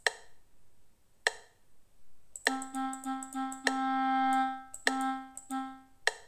É moi importante que escoitedes a claqueta de fondo (ou metrónomo), para levar a conta do número de pulsos que ocupa cada son e poder asocialo a unha figura musical determinada.